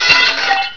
metal5.wav